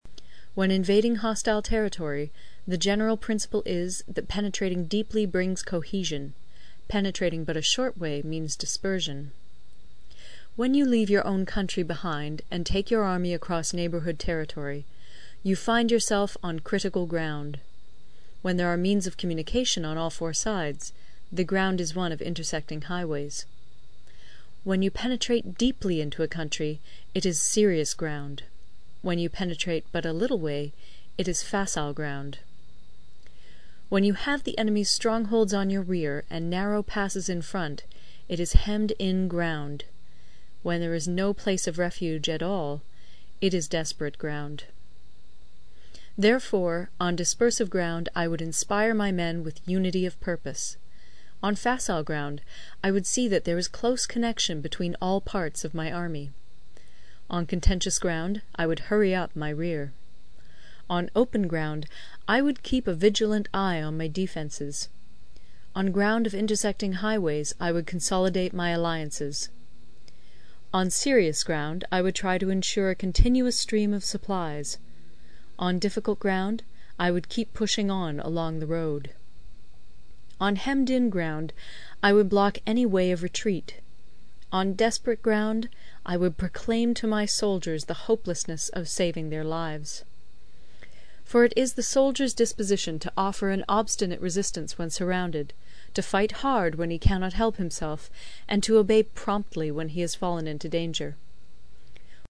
有声读物《孙子兵法》第67期:第十一章 九地(6) 听力文件下载—在线英语听力室